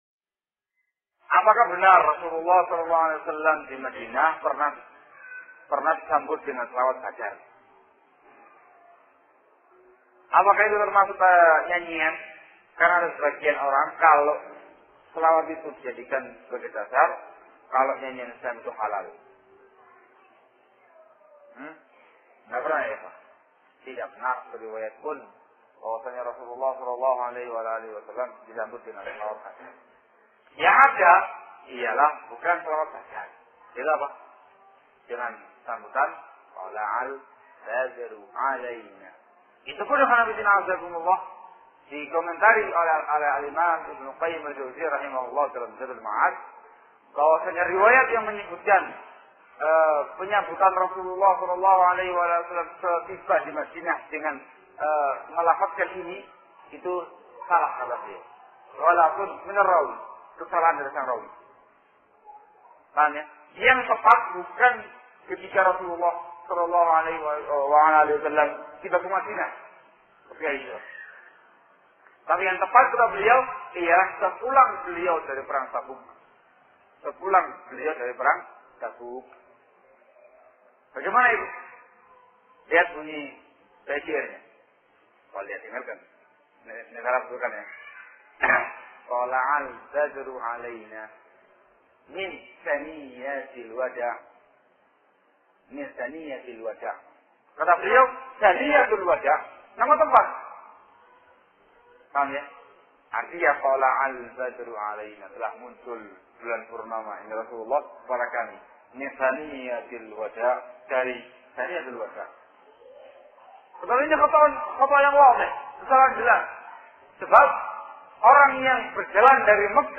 ℹ Kajian Rutin Adabul Murod di Jojoran - Surabaya.